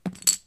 chipsHandle6.ogg